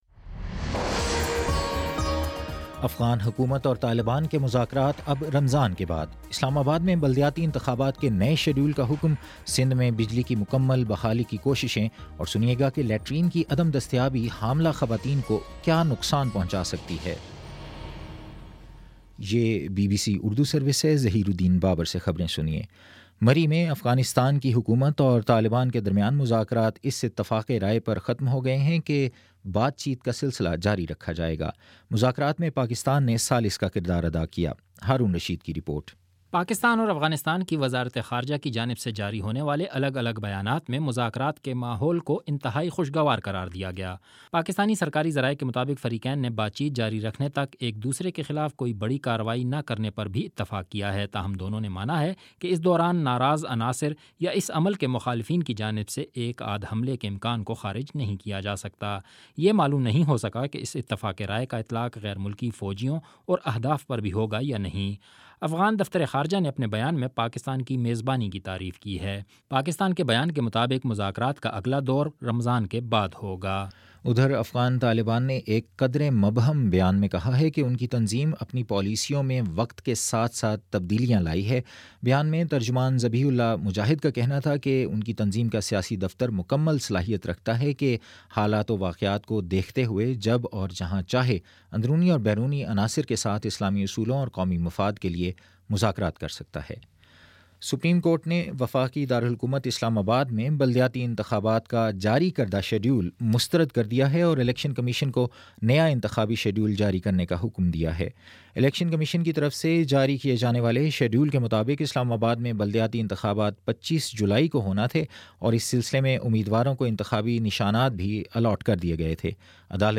جولائی 8: شام پانچ بجے کا نیوز بُلیٹن